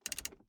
На этой странице собраны звуки работающей газовой колонки – от розжига до равномерного гудения.
Звук повышение температуры нагрева